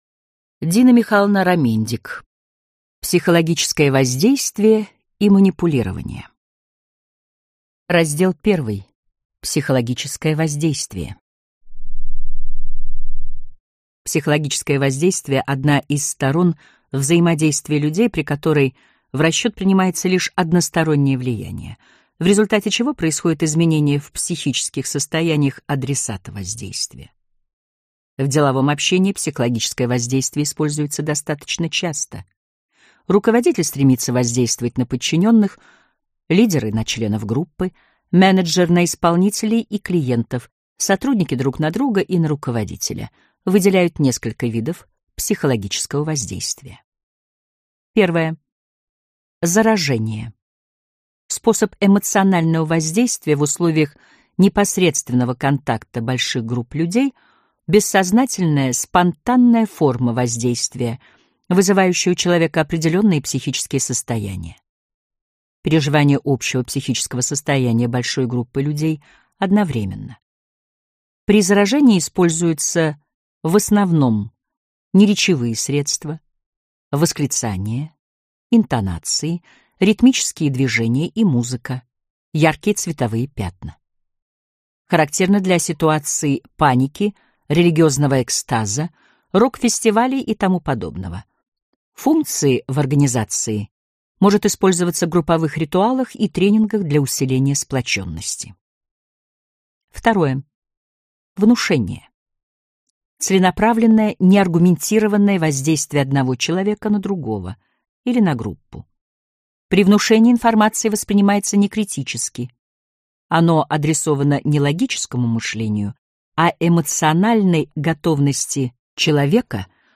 Аудиокнига Психологическое воздействие и манипулирование | Библиотека аудиокниг